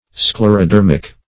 Search Result for " sclerodermic" : The Collaborative International Dictionary of English v.0.48: Sclerodermic \Scler`o*der"mic\, Sclerodermous \Scler`o*der"mous\, (Zool.)